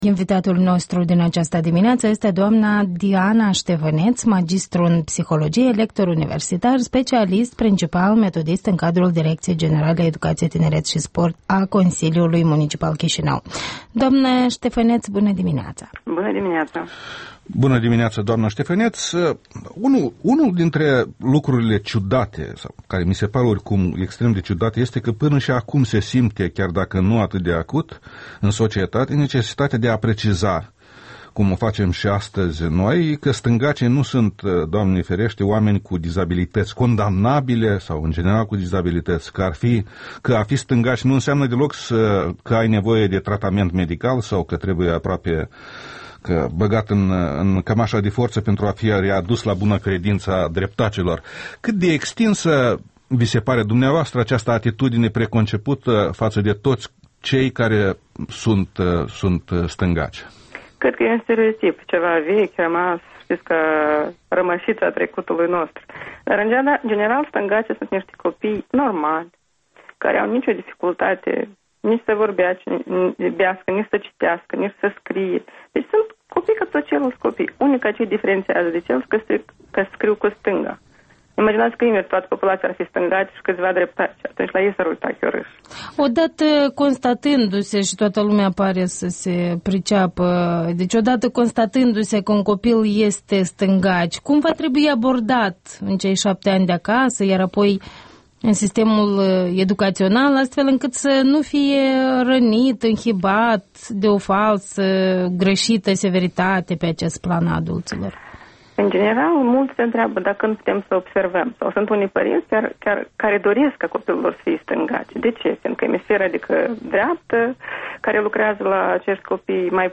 Interviul matinal REL: cu psiholoaga